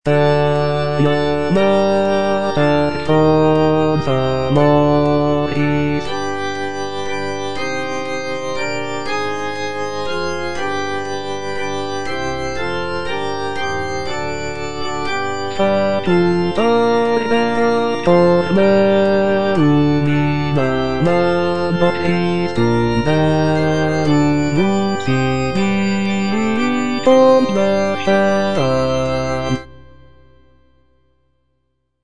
G.P. DA PALESTRINA - STABAT MATER Eja Mater, fons amoris (bass II) (Voice with metronome) Ads stop: auto-stop
sacred choral work